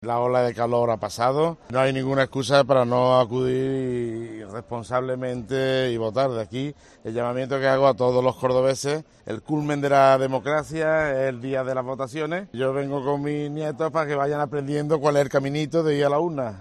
AUDIO: Declaraciones de Jesús Aguirre tras votar en Córdoba